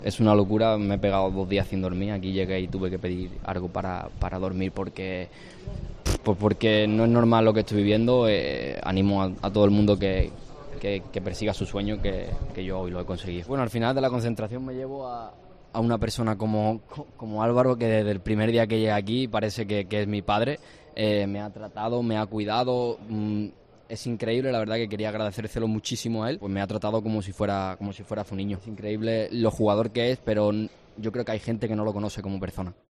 EN ZONA MIXTA